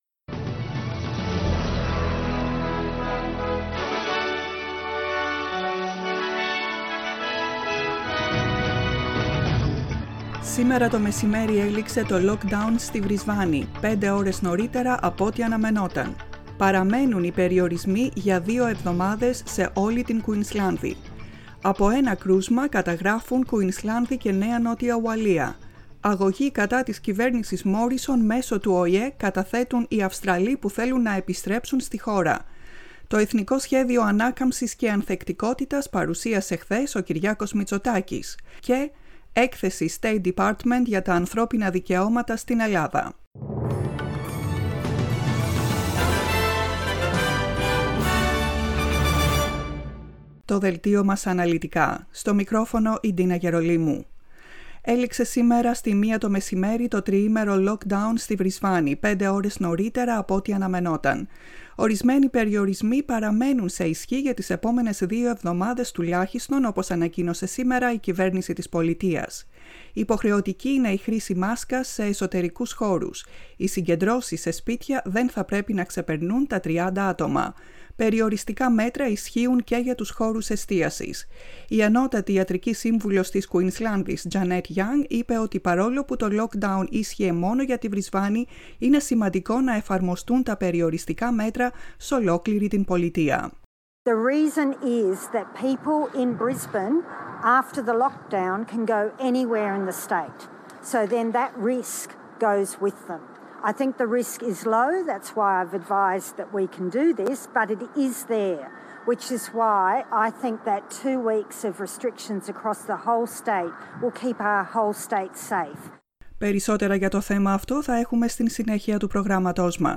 The main bulletin of the day from the Greek Program.